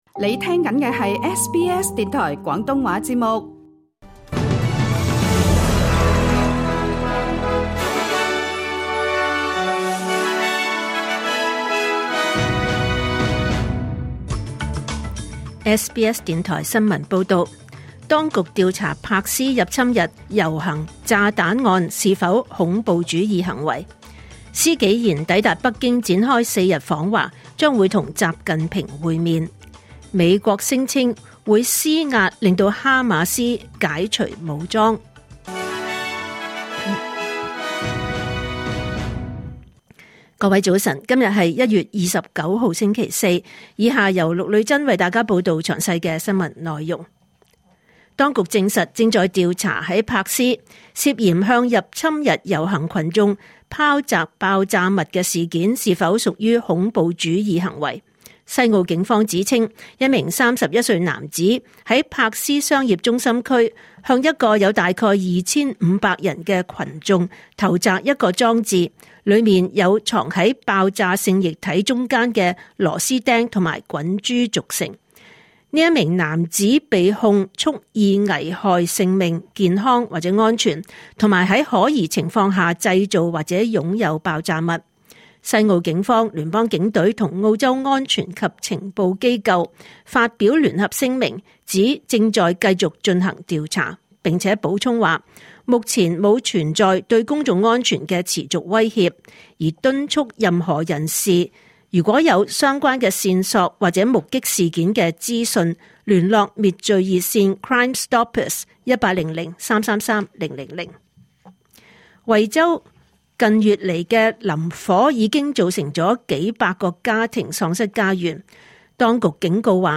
2026年1月29日SBS廣東話節目九點半新聞報道。